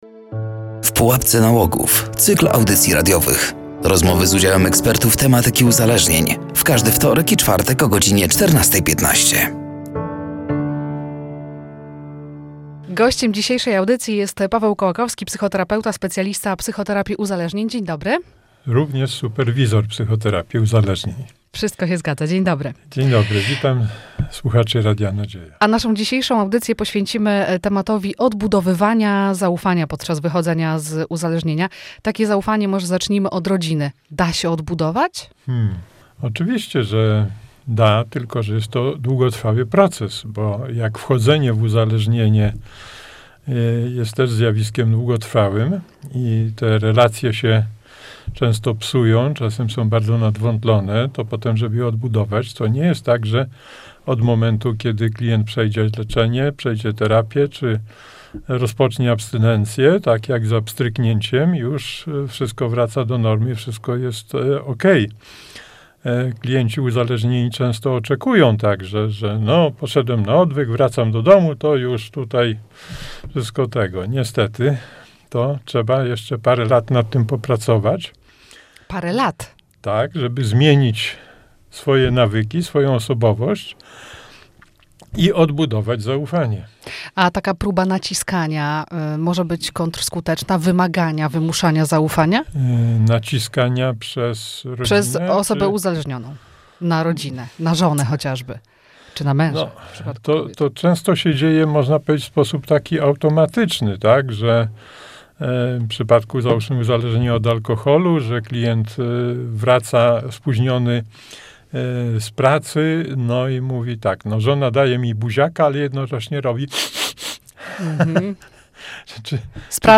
“W pułapce nałogów” cykl audycji radiowych poświęconych profilaktyce uzależnień wśród dzieci i młodzieży. Rozmowy z udziałem ekspertów tematyki uzależnień.